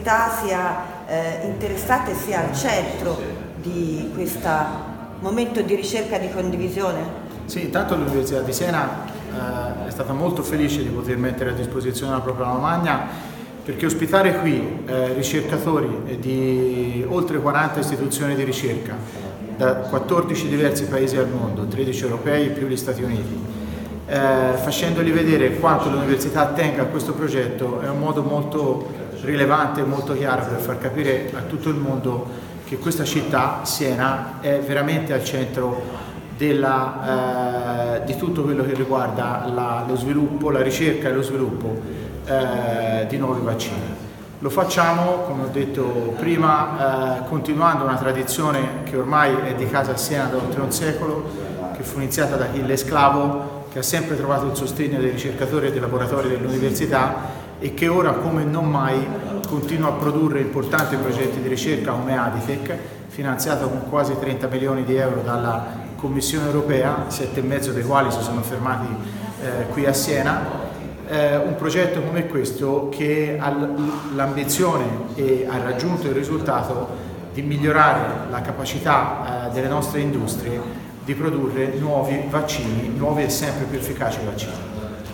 Le interviste:
Il Rettore Francesco Frati